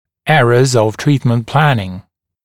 [‘erəz əv ‘triːtmənt ‘plænɪŋ][‘эрэз ов ‘три:тмэнт ‘плэнин]ошибки в планировании лечения